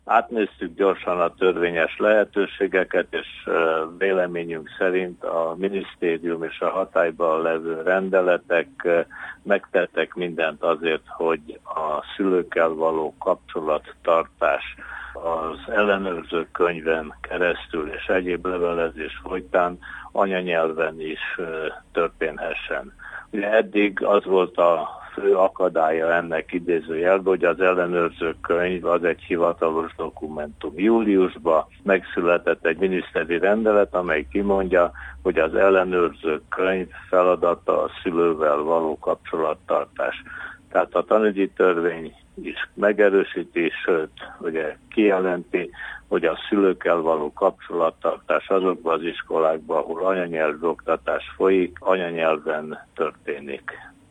Hallgassa meg Király András nyilatkozatát: